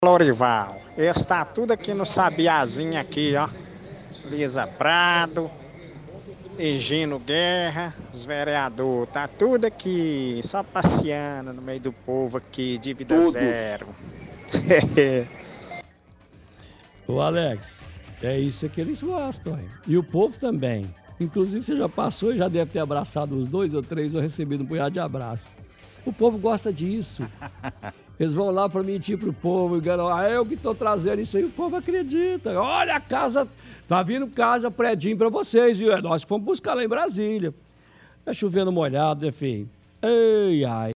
– Ouvinte manda áudio do Mutirão dívida zero que está acontecendo na arena Sabiazinho.